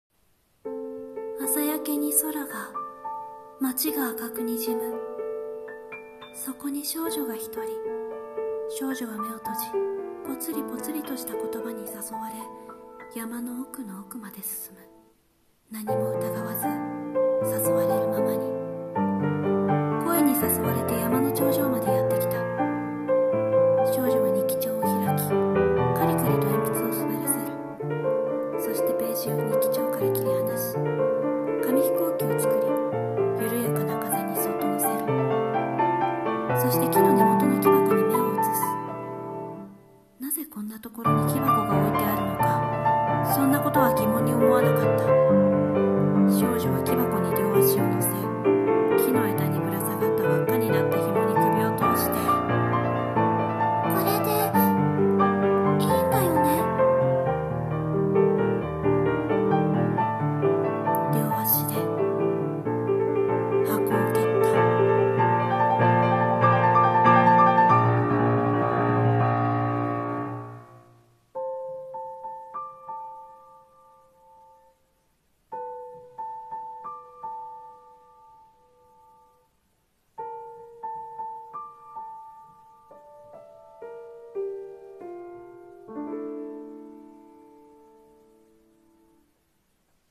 【一人声劇】深夜廻 黄昏